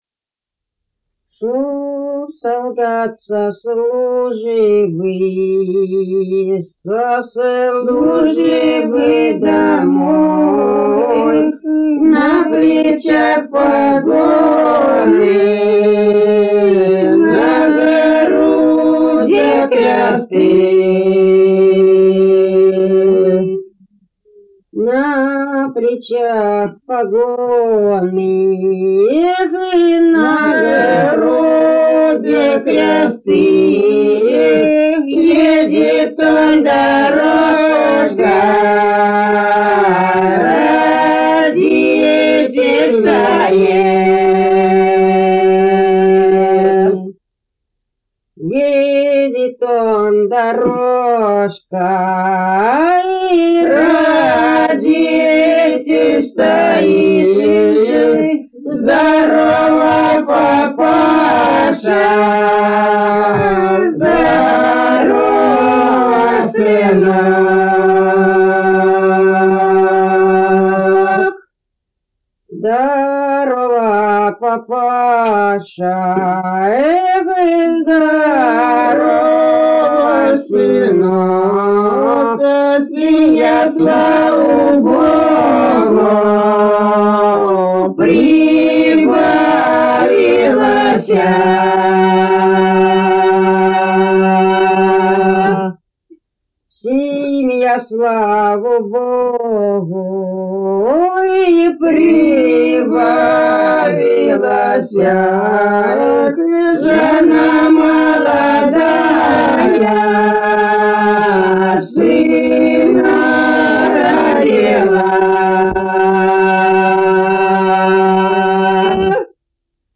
Народные песни Касимовского района Рязанской области «Шёл солдат со службы», протяжная.